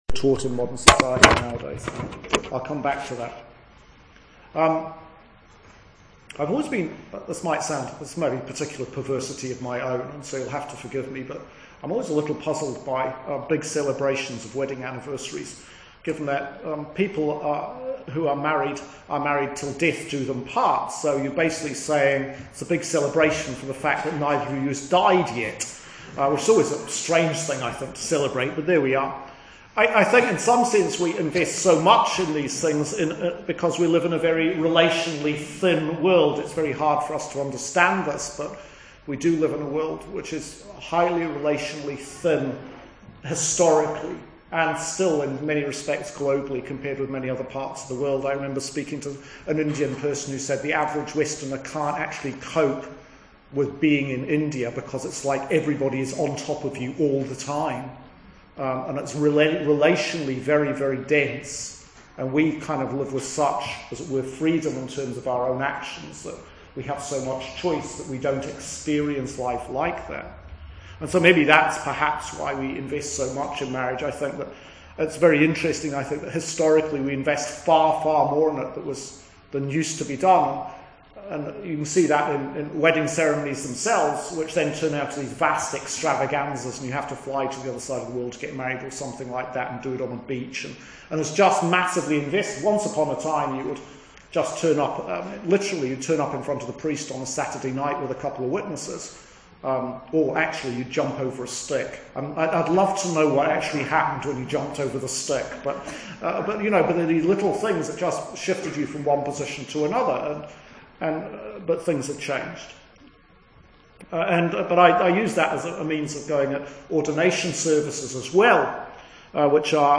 Sermon for Trinity 2, Year C 2019